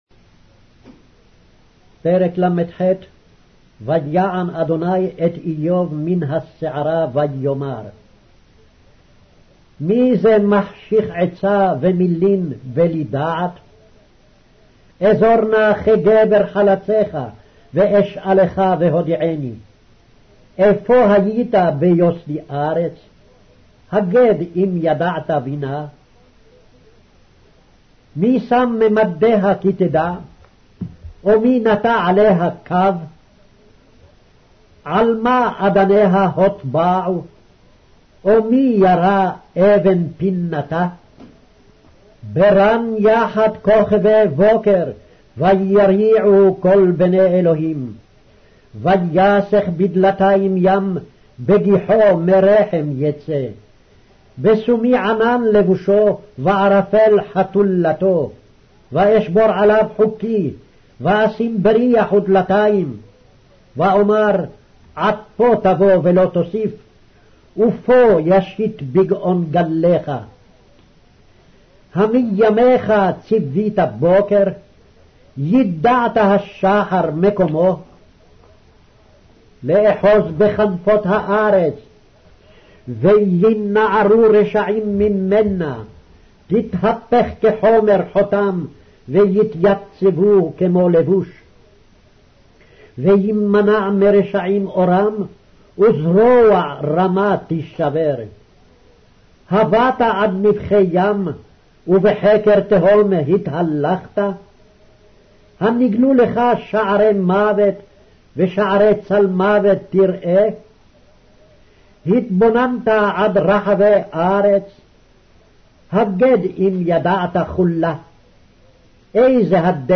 Hebrew Audio Bible - Job 28 in Gntbrp bible version